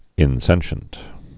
(ĭn-sĕnshənt)